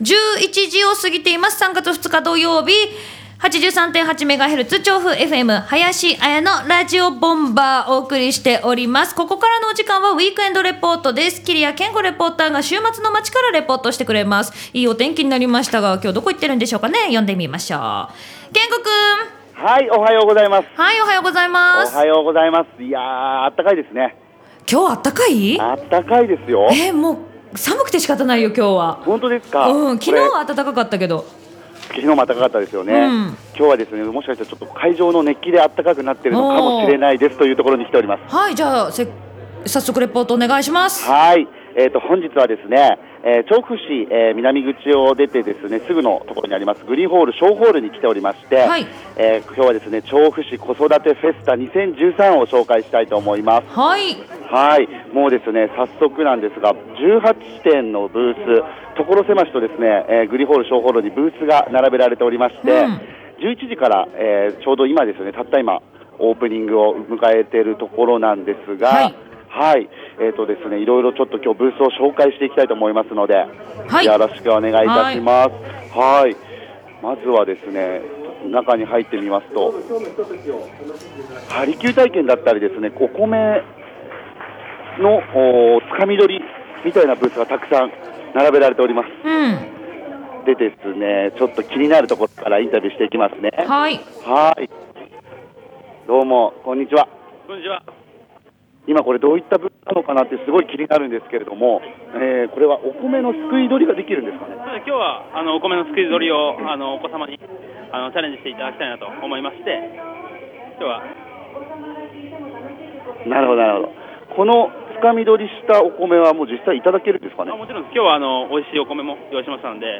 今週のウィークエンドレポートは ｢調布市子育てフェスタ2013｣をお届けしました！
会場となったグリーンホール小ホールには、 朝から沢山の親子連れで賑わっていましたよ！ このイベントでは、お子さんが楽しめる体験コーナーや クッキングプログラムとして、パパがお子さんに作るパパご飯、 ママが疲れを癒すことができるヨガ講習、親子体操など、 色々なプログラムが用意されていました！